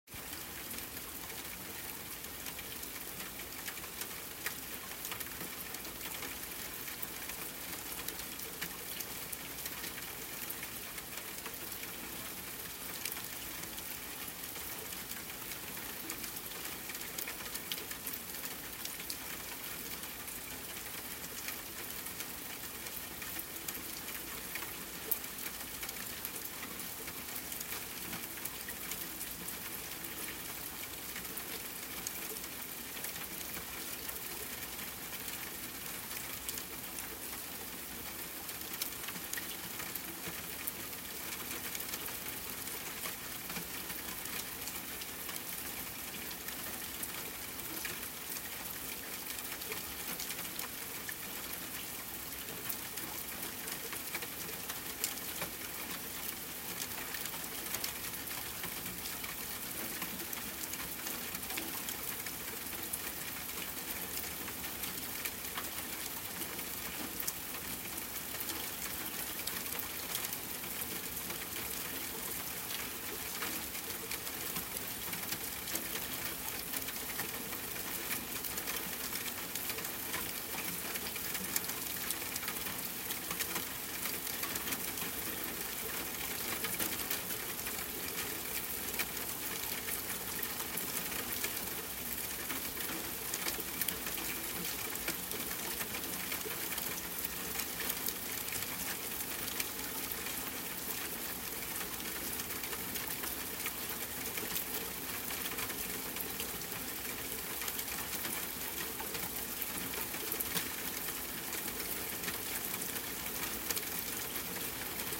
Audio - Soft Rain
Nature Audio - No Distractions only Focus